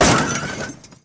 Collision2.mp3